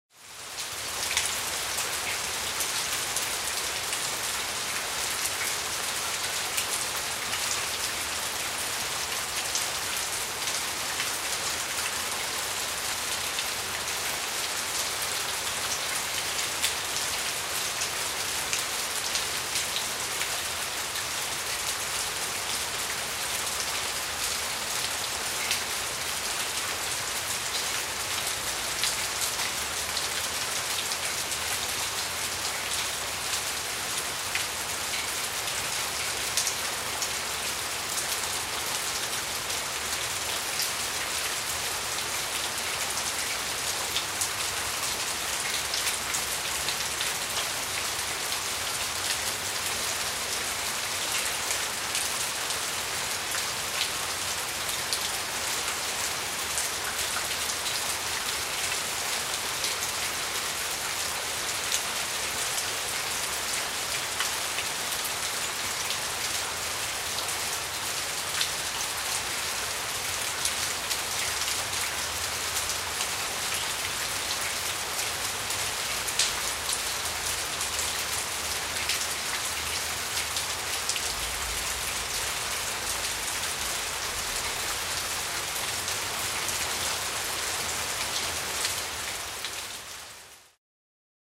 Звук дождя по асфальту и капли с крыши